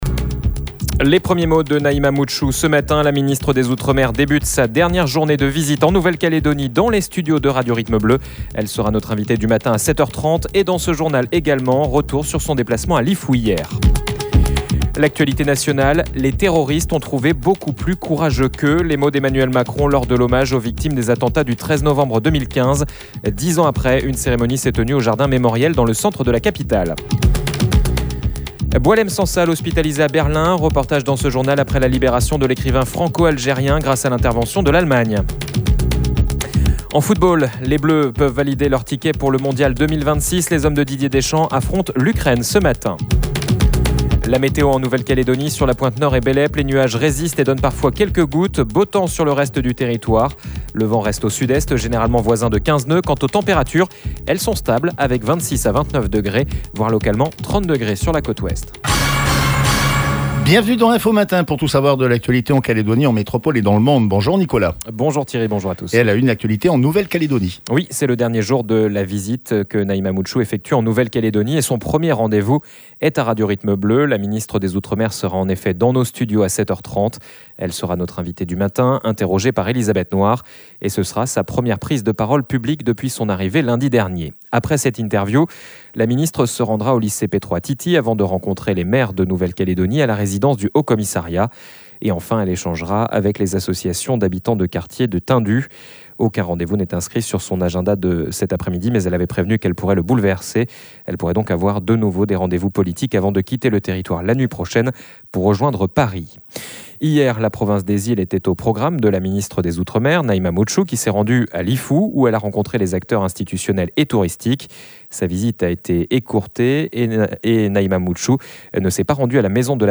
La ministre des Outremers sera en effet dans nos studios à 7h30.